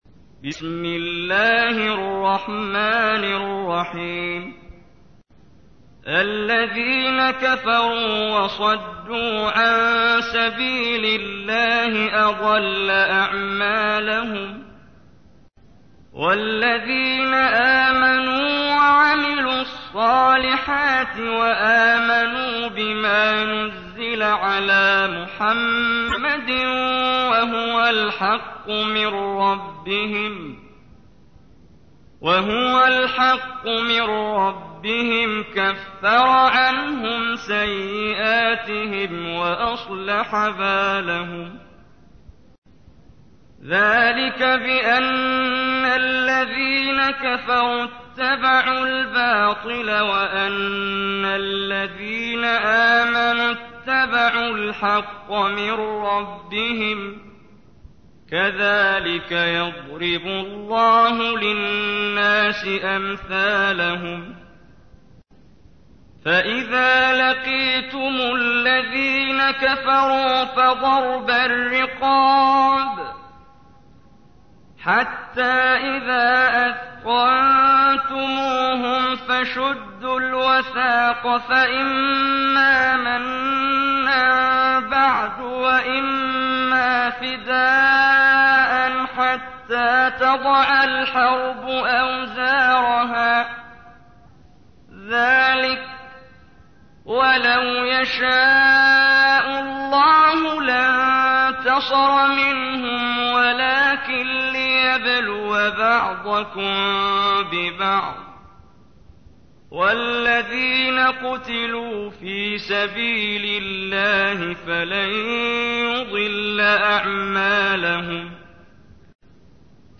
تحميل : 47. سورة محمد / القارئ محمد جبريل / القرآن الكريم / موقع يا حسين